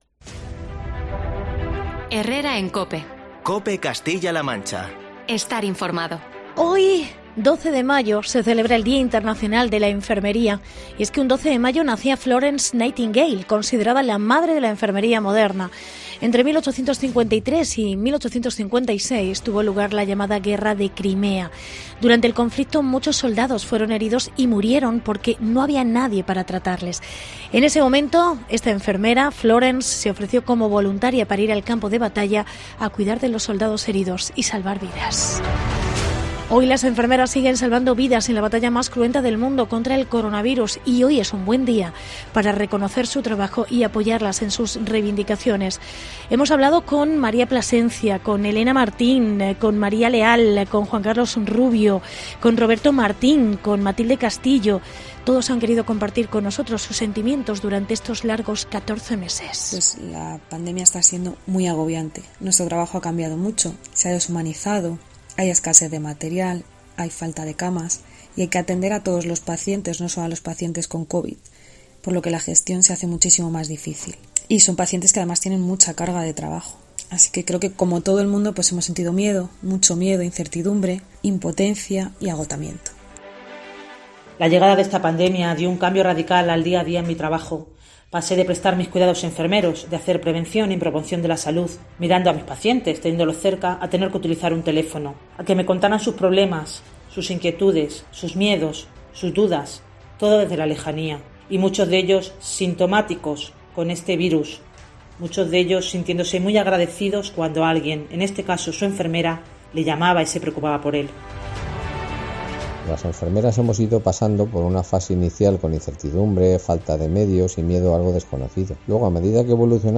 Reportaje enfermeras de Toledo